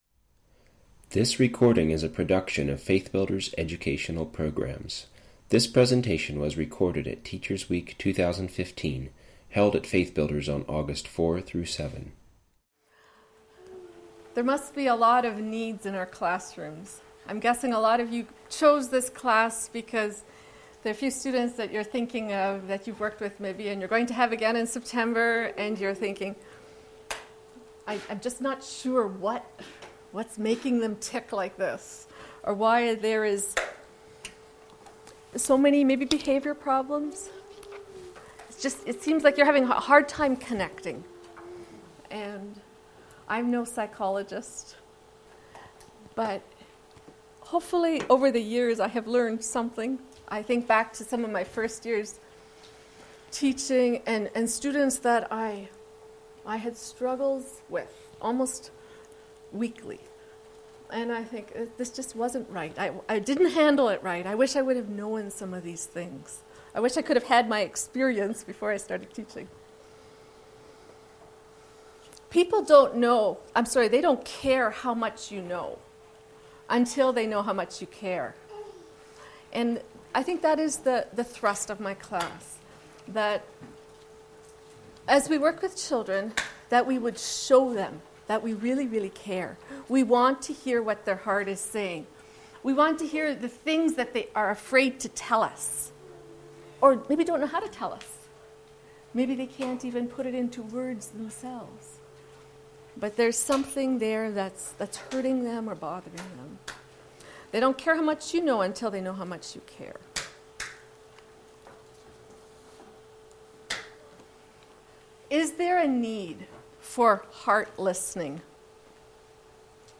Teachers Week 2015